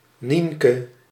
PronunciationDutch: [ˈniŋkə]
Nl-Nienke.ogg.mp3